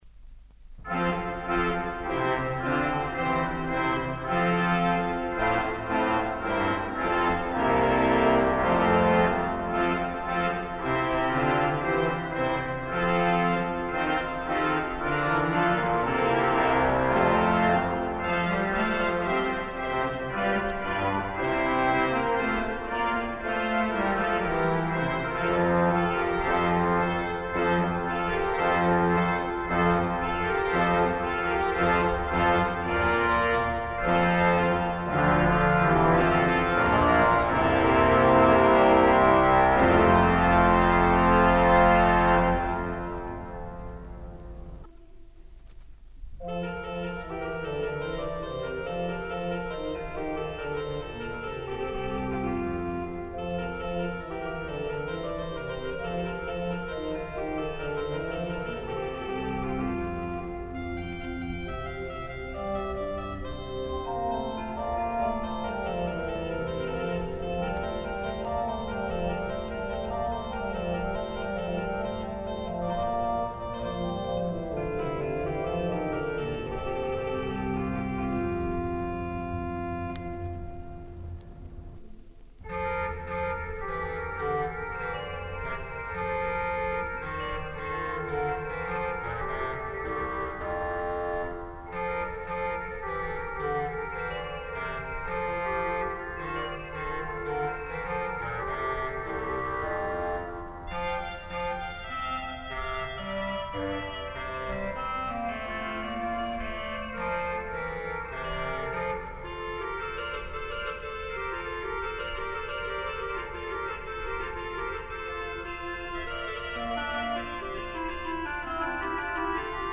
avant la restauration de 1972 - Une vieille cire !